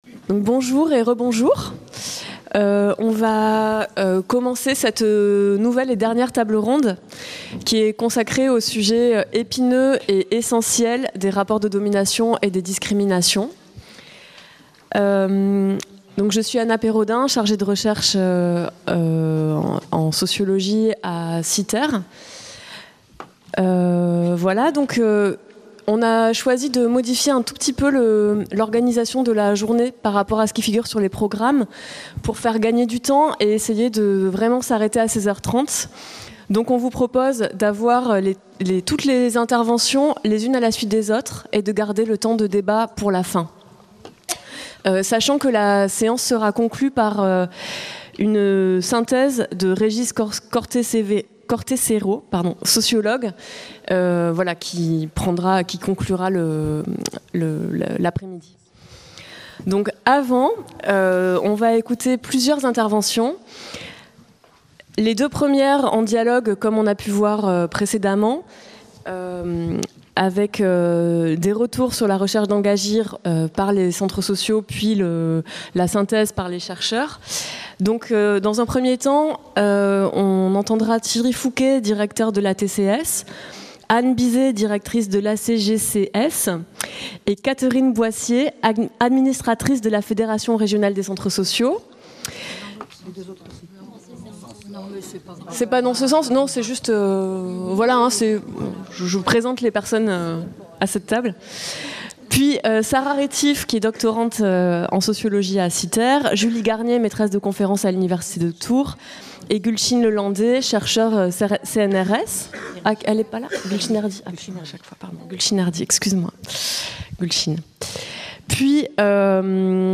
19- (Table ronde 4) : Retours sur la recherche Engagir.